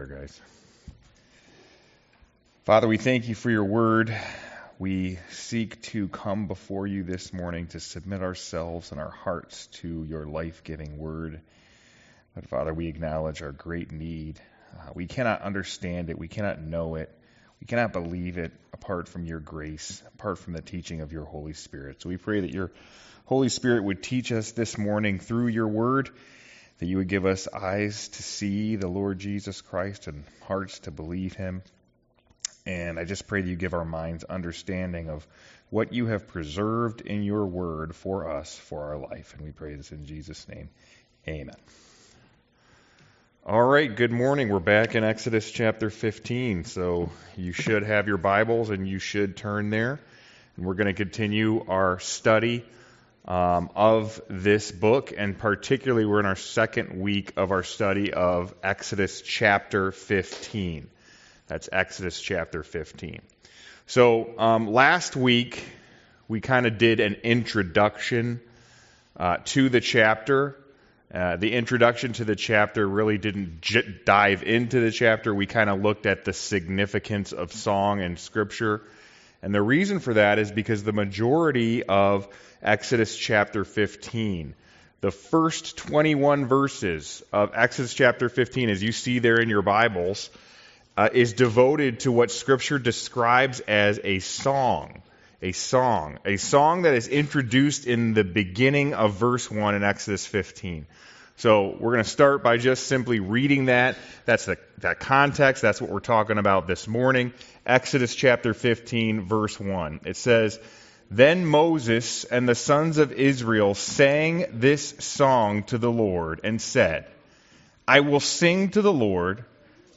Passage: Exodus 14 Service Type: Sunday School « Sorrento